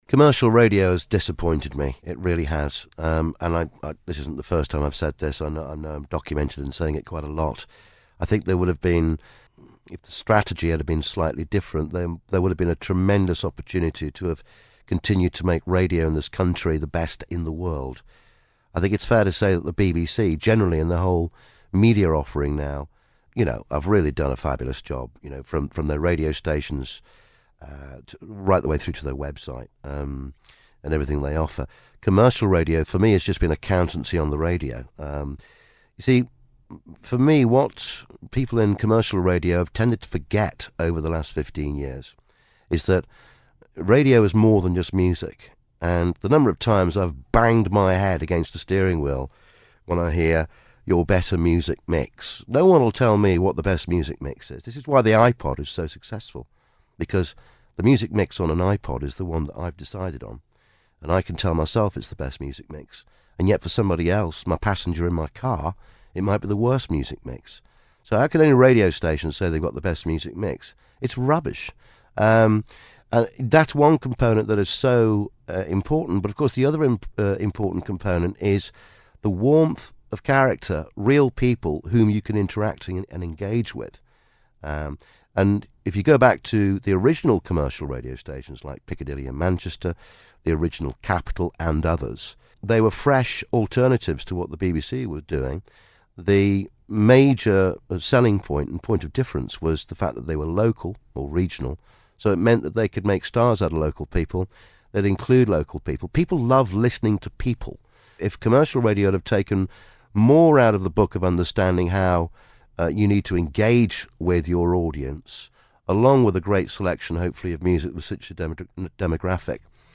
From an interview in 2010, Bruno Brookes gives his honest and very frank thoughts on the state of commercial radio.